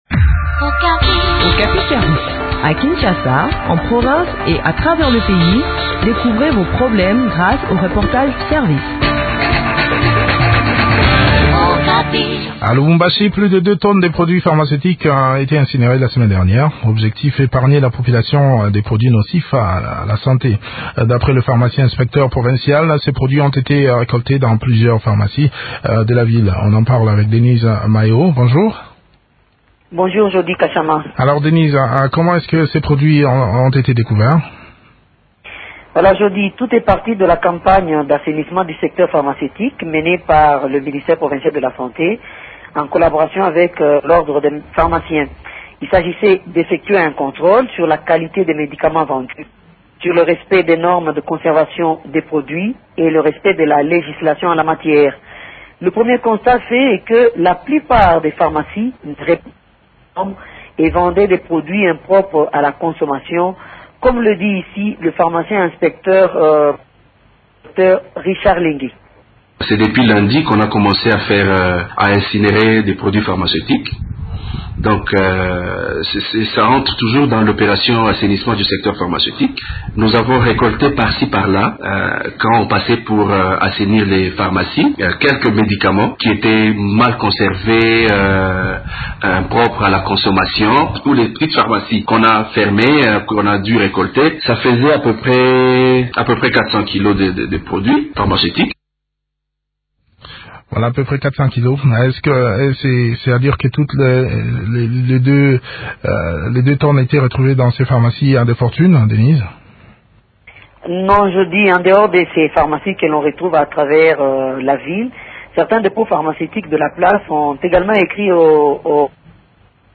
Le point du sujet dans cet entretrien